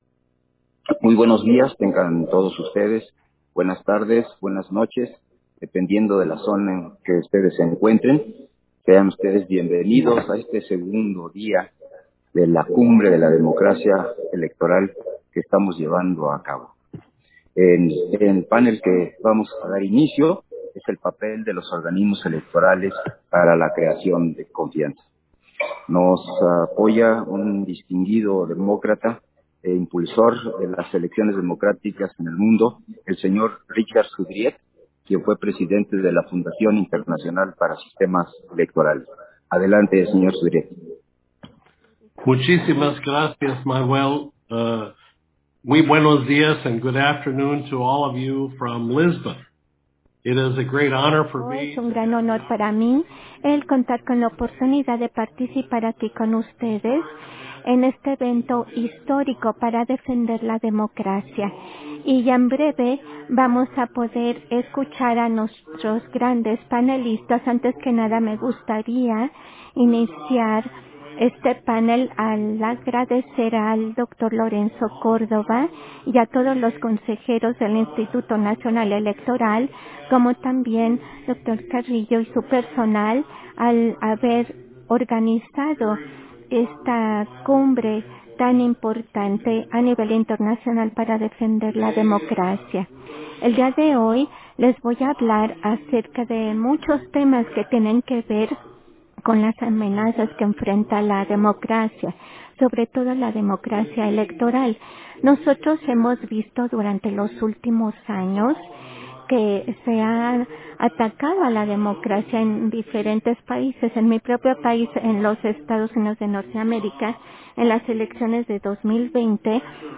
210922_AUDIO_PANEL-DE-DISCUSIÓN-DE-LA-CUMBRE-GLOBAL-DE-LA-DEMOCRACIA-ELECTORAL